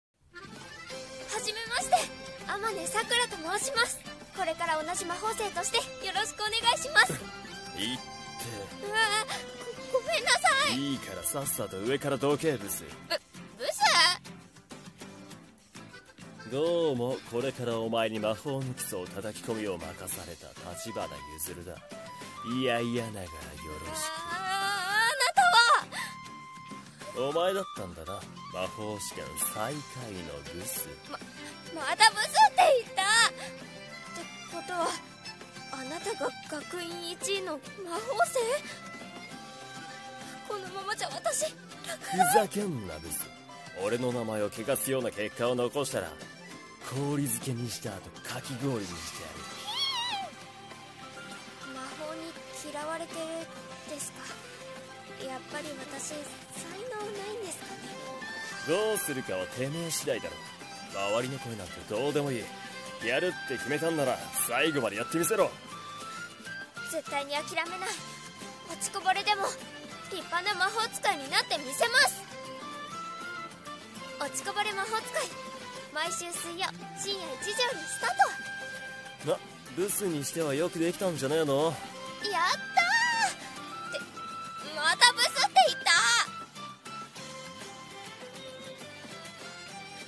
【CM風声劇】落ちこぼれ魔法使い【掛け合い】